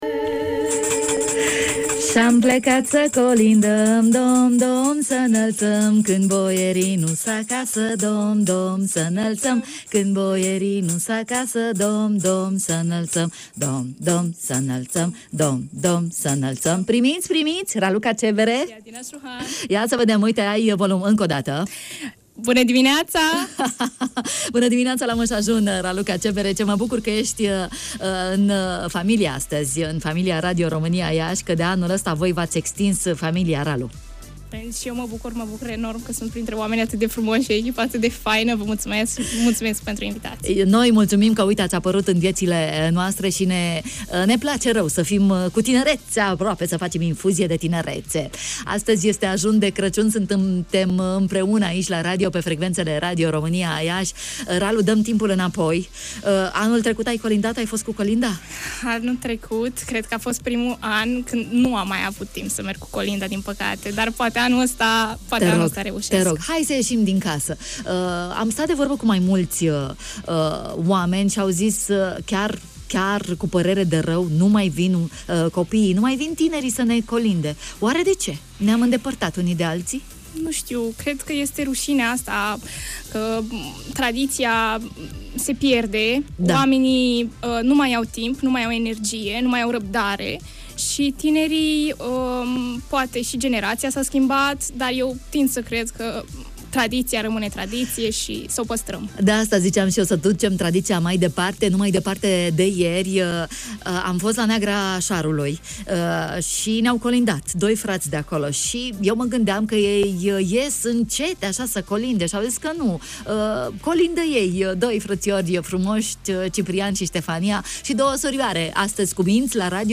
reporter ”Incubator” ne-a colindat, în Ajunul Crăciunului